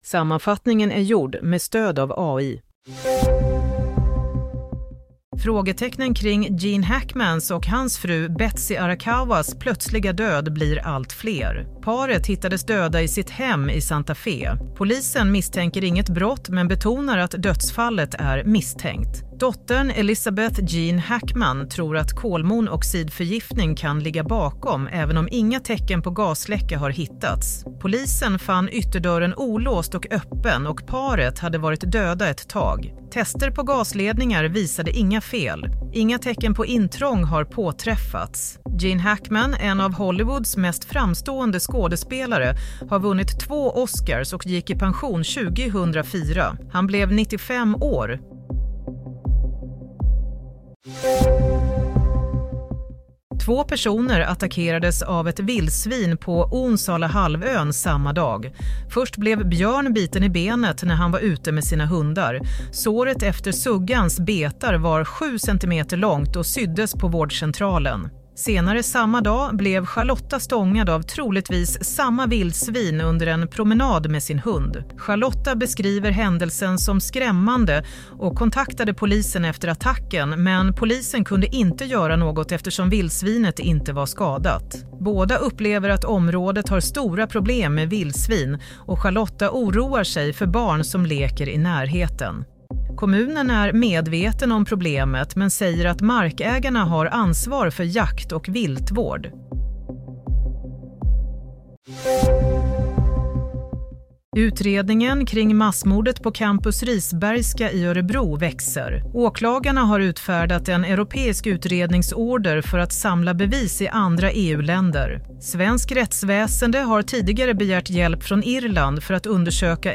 Nyhetssammanfattning – 27 februari 22:00